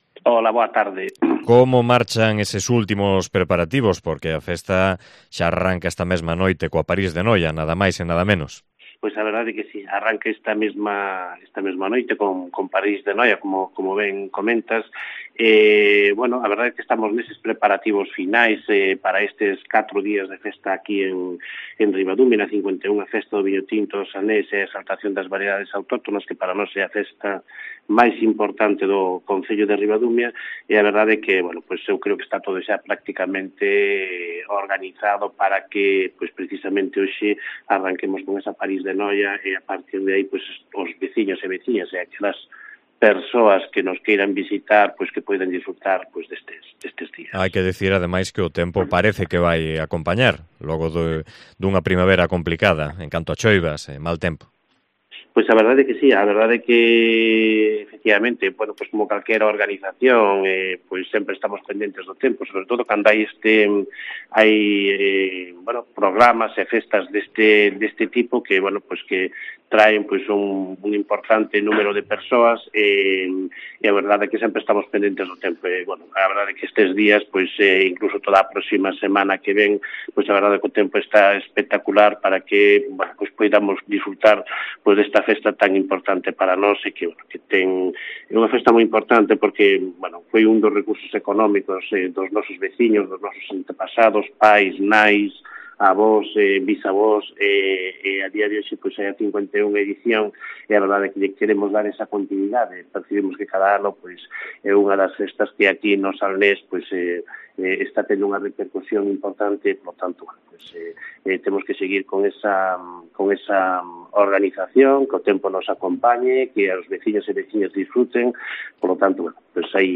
Entrevista con el alcalde de Ribadumia, David Castro. Festa do Viño Tinto Barrantes